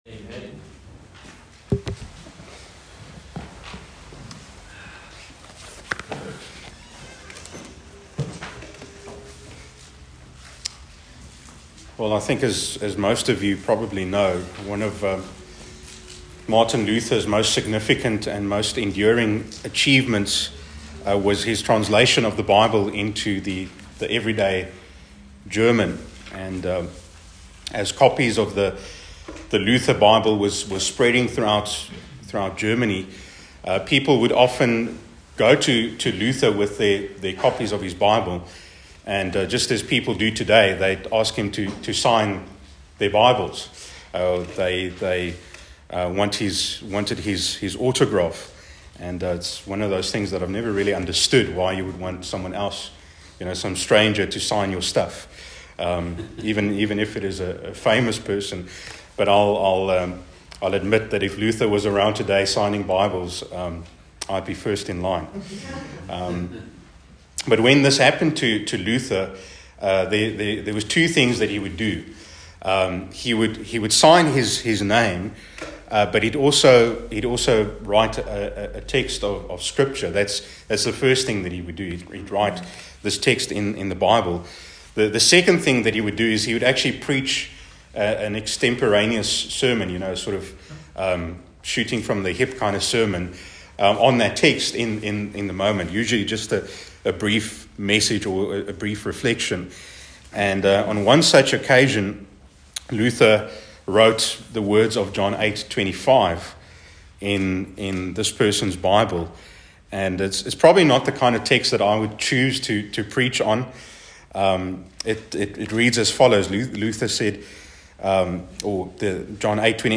A message from the series "Sola 5 series."